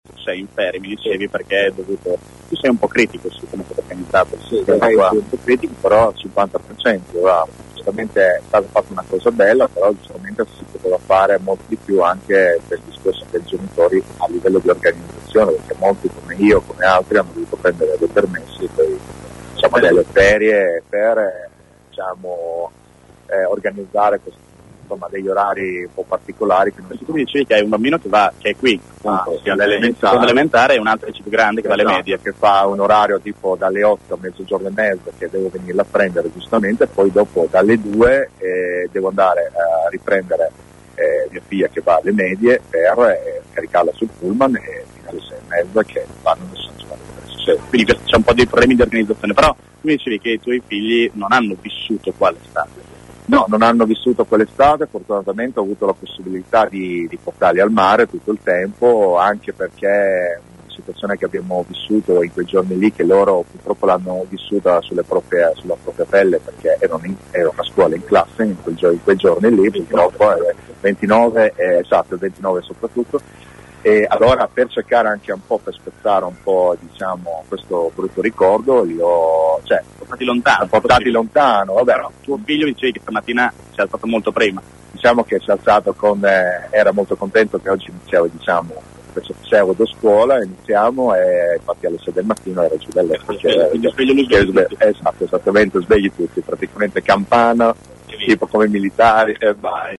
Ecco alcune delle voci raccolte questa mattina dai nostri inviati.